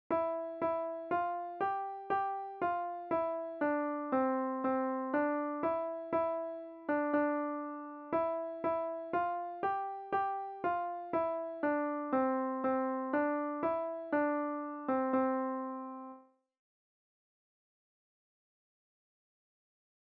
Point/click/use external mouse or fingers on a touch screen to play the piano keys.